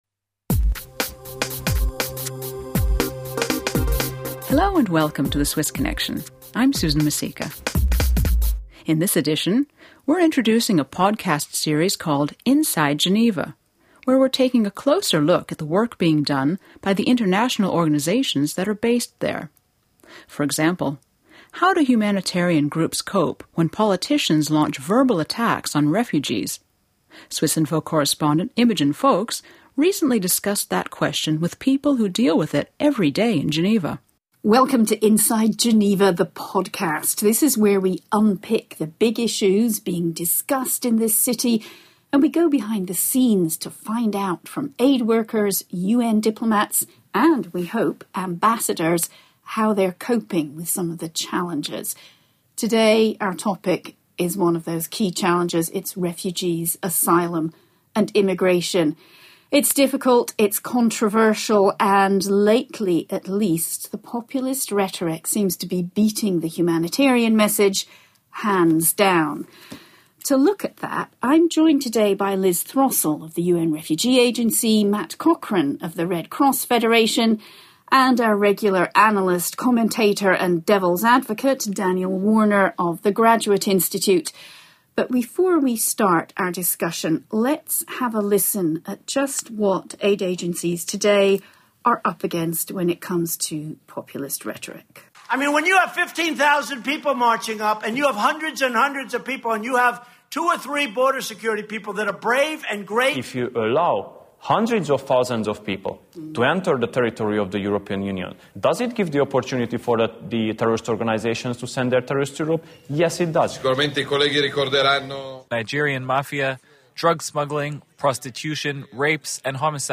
four-way conversation